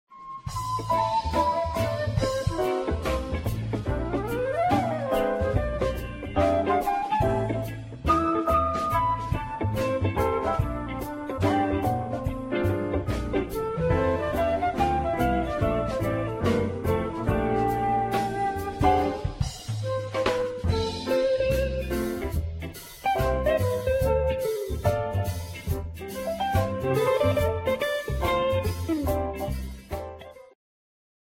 ジャンル Jazz
Progressive
癒し系
東洋とも西洋とも言えない新しい宇宙サウンドを聴かせてくれる